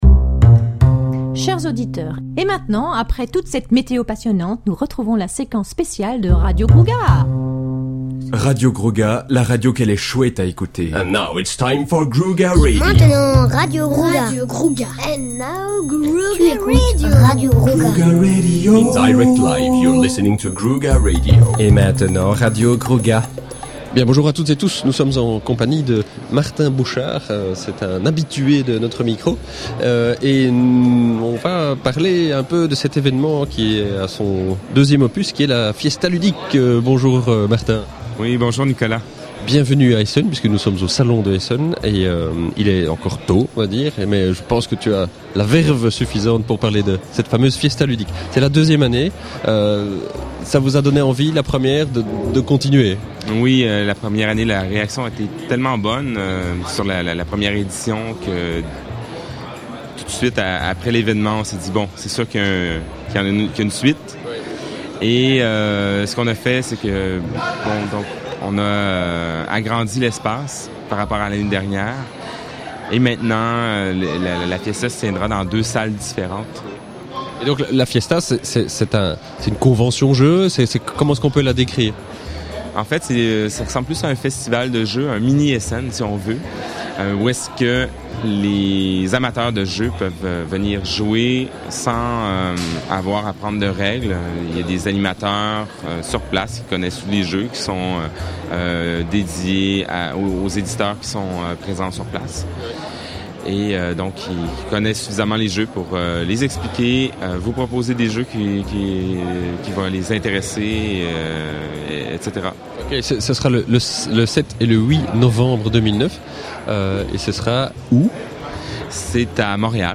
(enregistré au salon Spiel de Essen édition 2009)